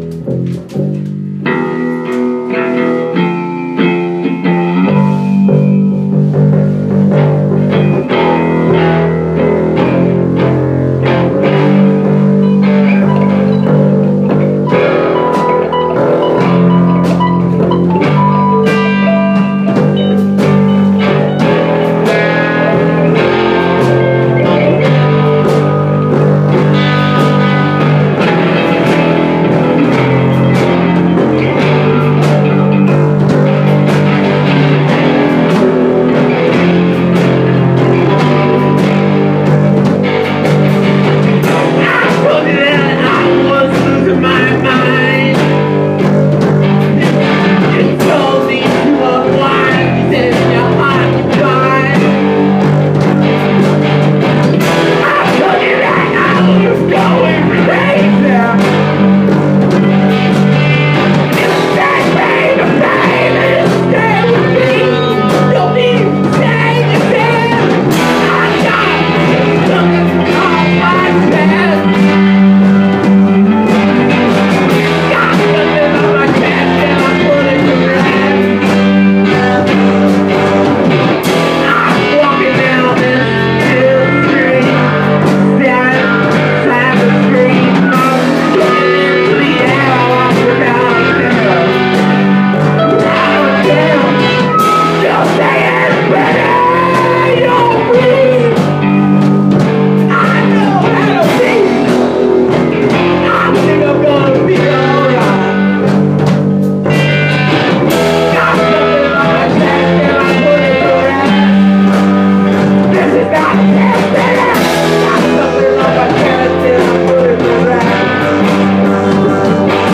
5 piece band
vocals
Guitar
Bass
Drums